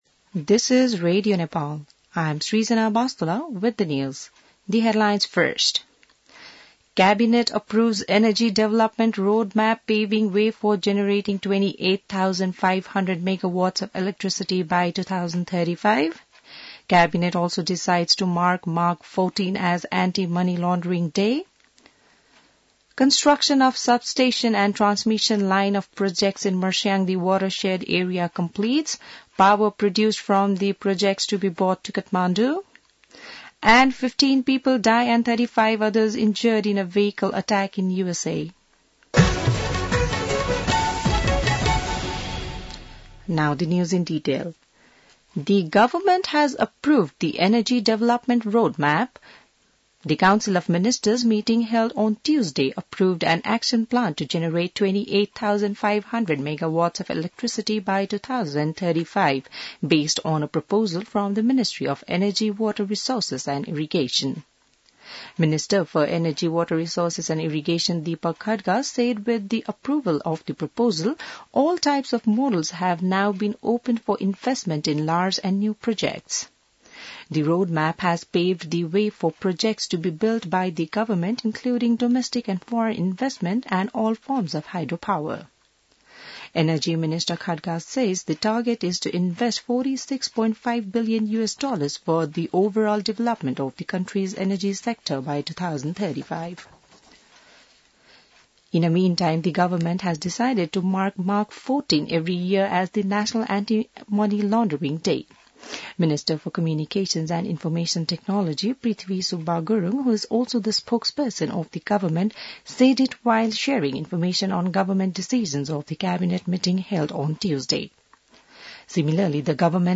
बिहान ८ बजेको अङ्ग्रेजी समाचार : १९ पुष , २०८१